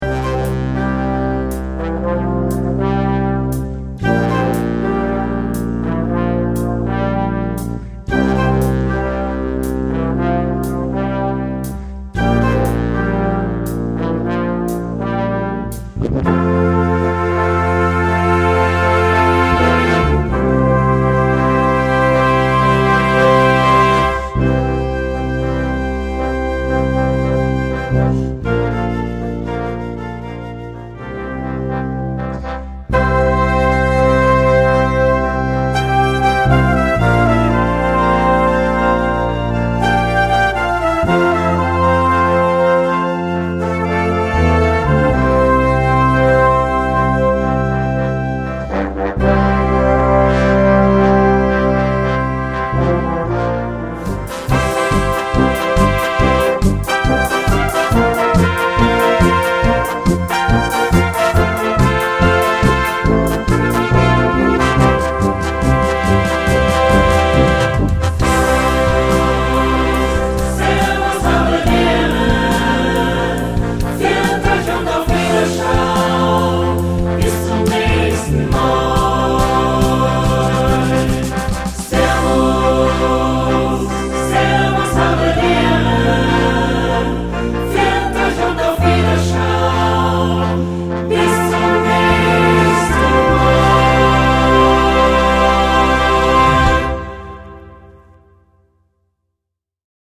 Gattung: Ballade für Blasorchester
Besetzung: Blasorchester